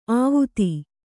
♪ āvuti